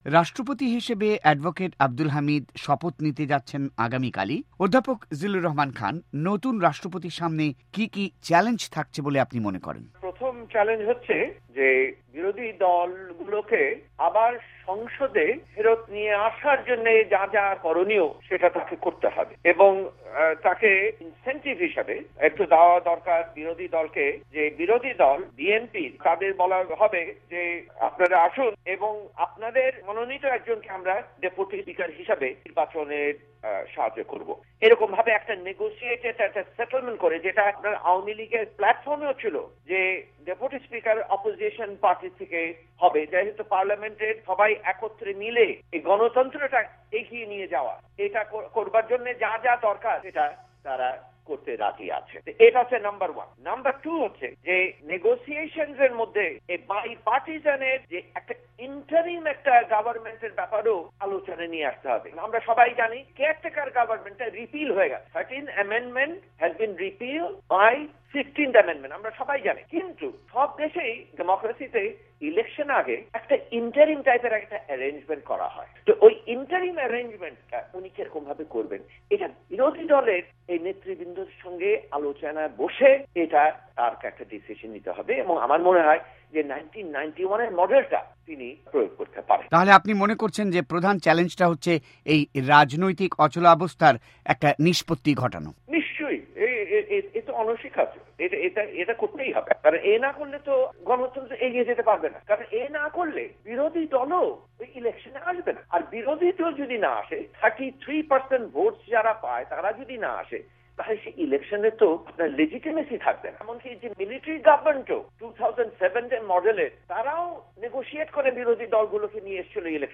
সাক্ষাতকার